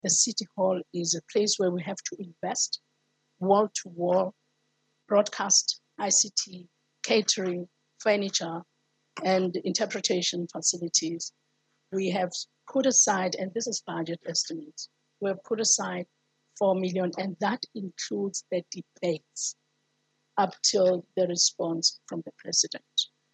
The acting secretary of Parliament, Baby Tyawa says extra expenses have to be budgeted for, this year, after the fire at Parliament in January.